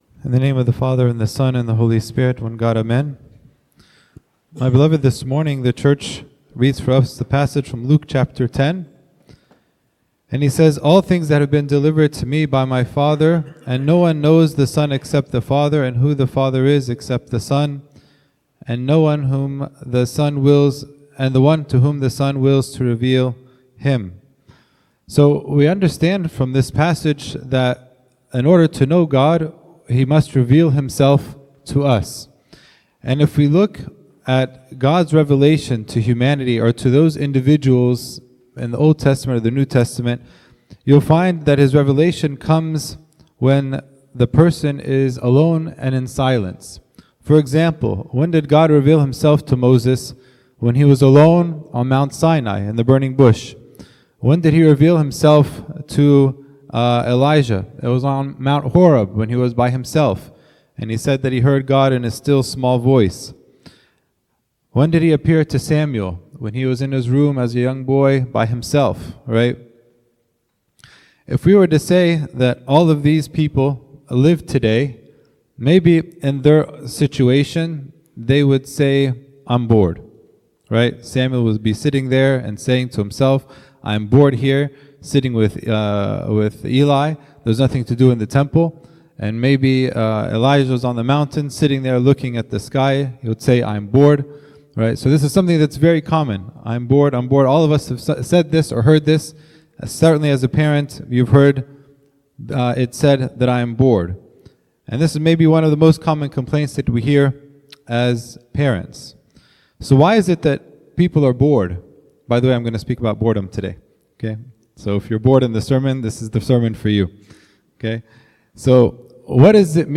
Content Type: Sermon